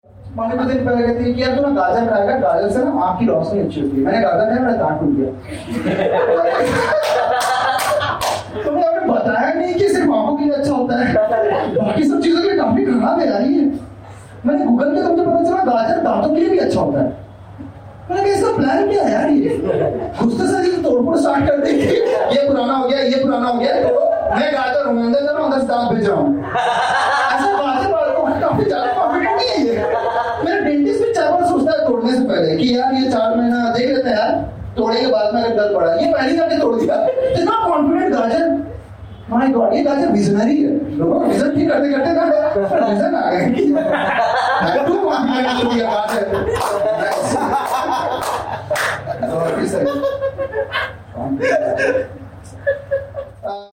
Acquired Taste | A Hindi Stand Up Comedy Audio Album
Gajar Mar 01, 2019, 12:00 AM Headliner Embed Embed code See more options Share Facebook X Subscribe Acquired Taste | A Hindi Stand Up Comedy Audio Album Hi, So, this is a compilation of jokes which I had performed in various open mics in last two years. Generally we audio record all the sets before going on stage, hence all the audios are phone recorded, that is why some of the clip might sound muffled.
How well the bit is going to be received depends on various factors, Sometimes we get lucky to perform in front of fully enthusiastic crowd, where the room is blasting with energy.